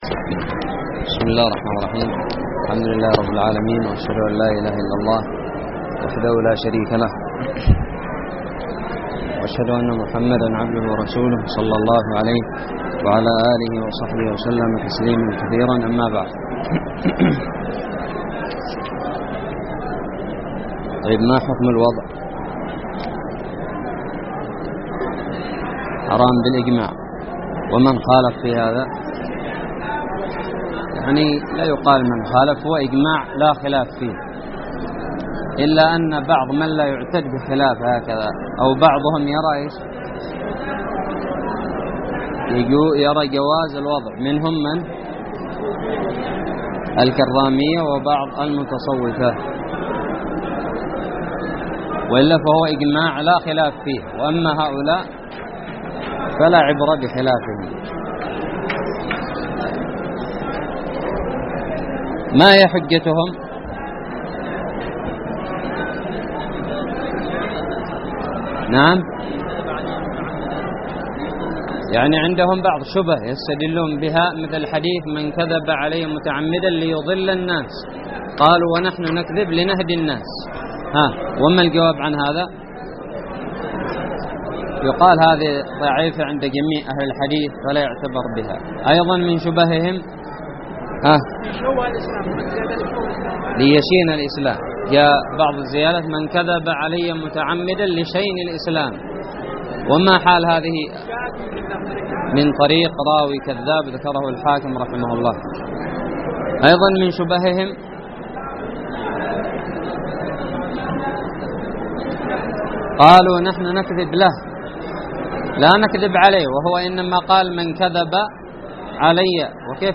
الدرس السادس والعشرون من شرح كتاب نزهة النظر
ألقيت بدار الحديث السلفية للعلوم الشرعية بالضالع